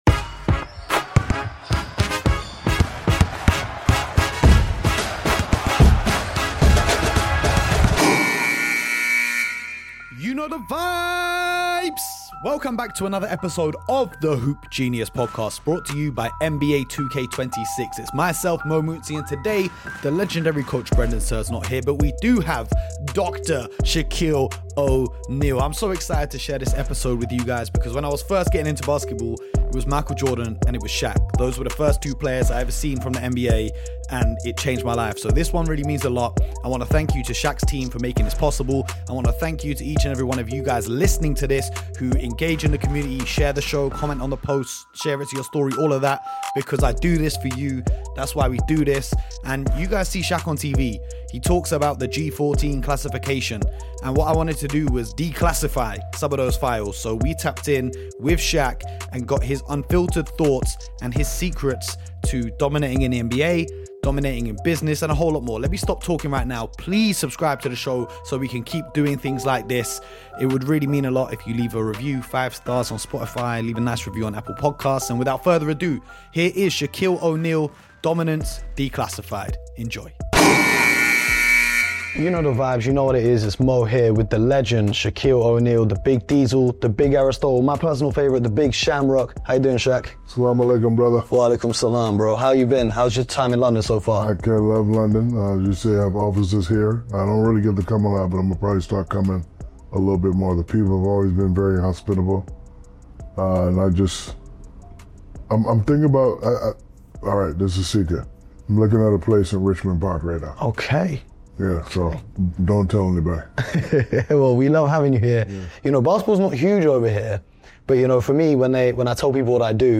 interview: Shaq EXPOSES Why the NBA Went Soft
In this exclusive interview, NBA legend Shaquille O’Neal sits down for a wide-ranging, unfiltered conversation covering basketball dominance, championship mentality, business success, and life advice.